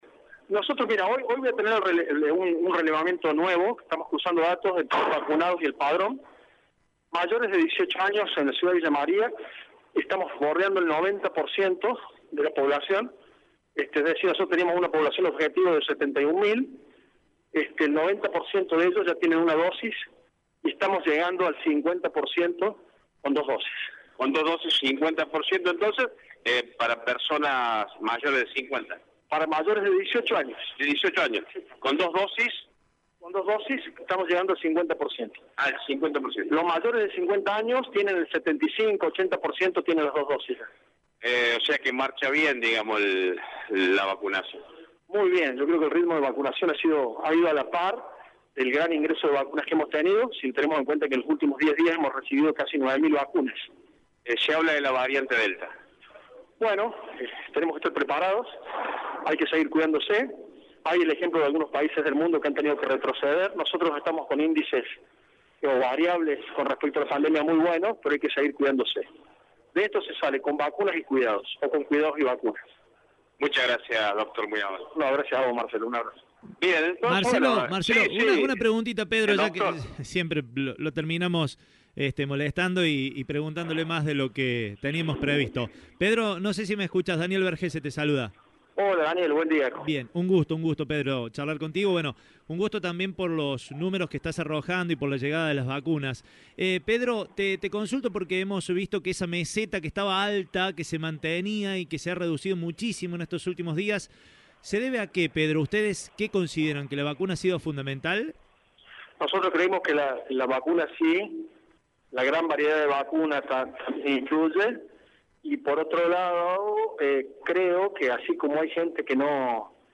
En diálogo con Radio Show, el secretario de Salud del municipio, el Dr. Pedro Trecco dio a conocer porcentajes de personas alcanzadas con una y dos dosis de vacunas contra el covid-19.